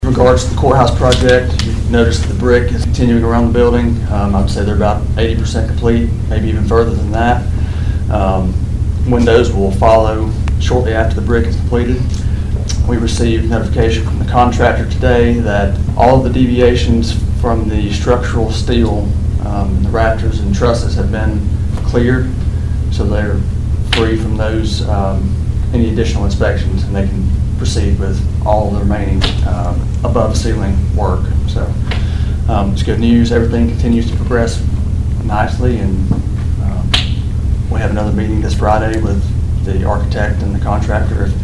During Monday’s County Commission meeting, Cherokee County Administrator Daniel Steele gave an update on the construction of the new courthouse, currently under construction on East Main Street.